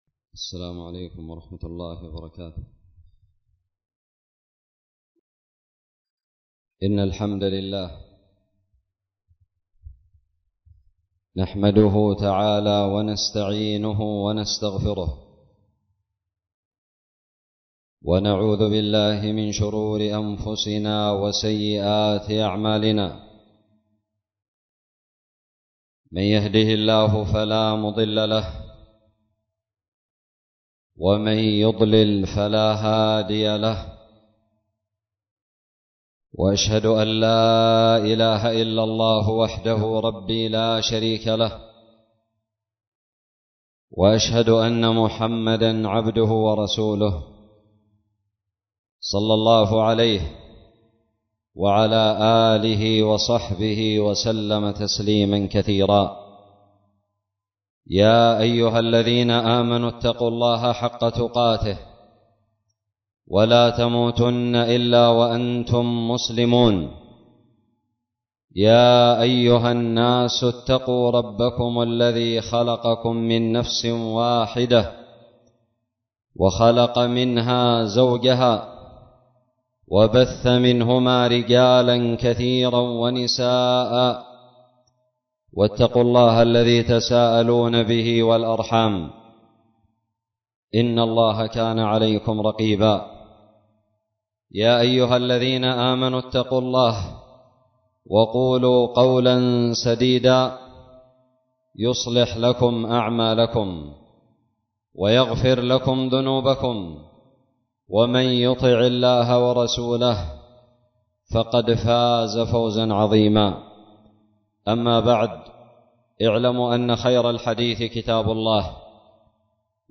خطب الجمعة
ألقيت بدار الحديث السلفية للعلوم الشرعية بالضالع في 1 ذو القعدة 1442هـ